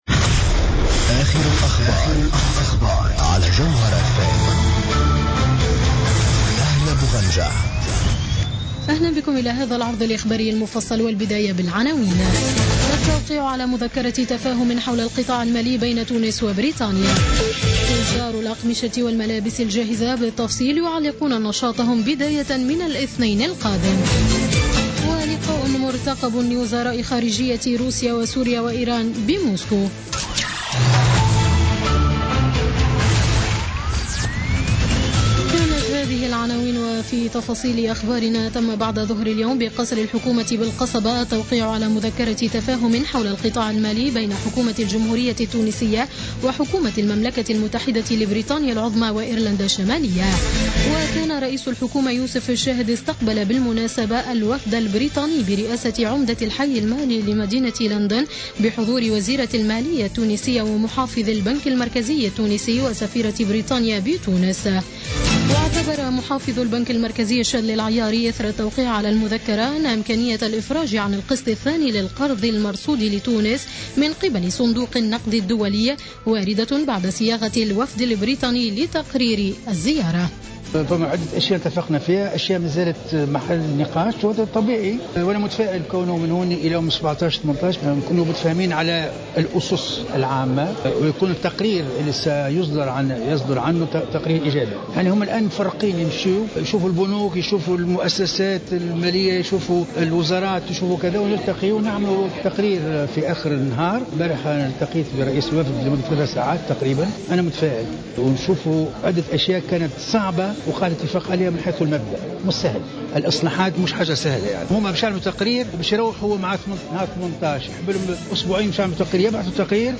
نشرة أخبار السابعة مساء ليوم الثلاثاء 11 أفريل 2017